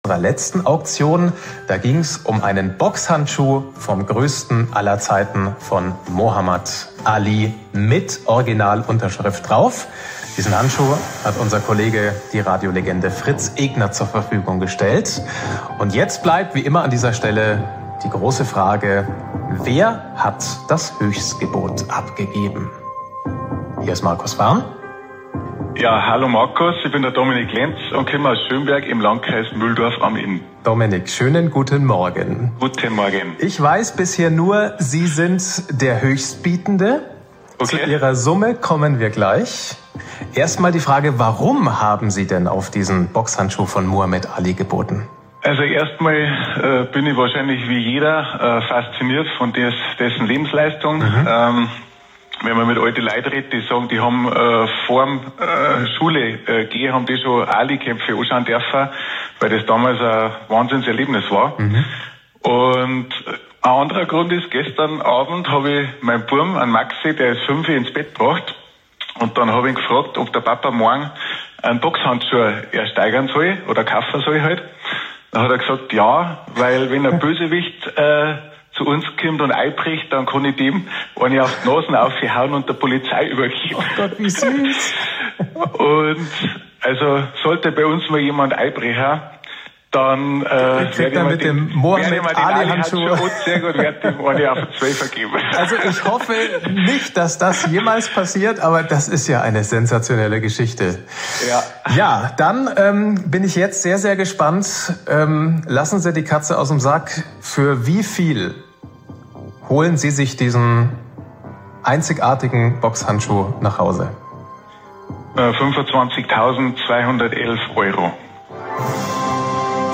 1. Interview mit Bayern1